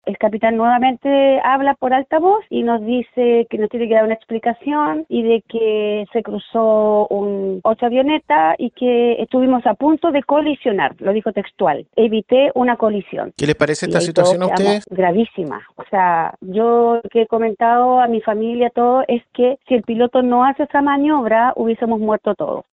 pasajera del avión